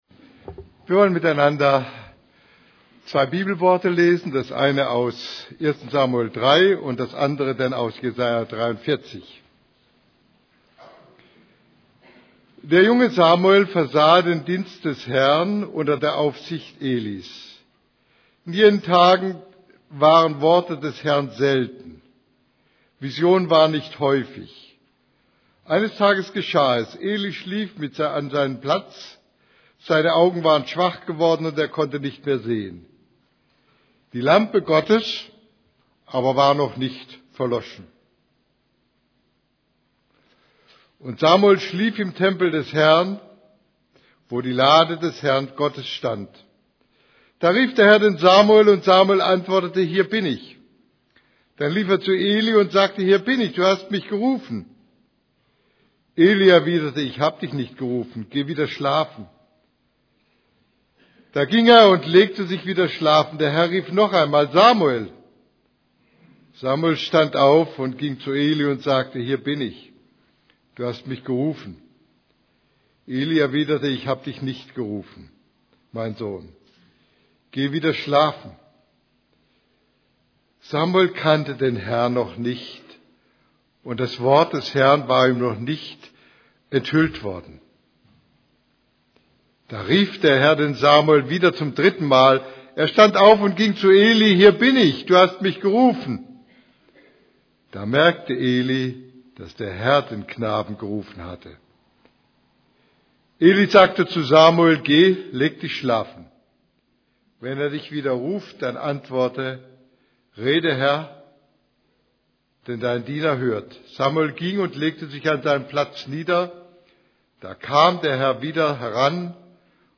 > Übersicht Predigten Auf Gott hören Predigt vom 21.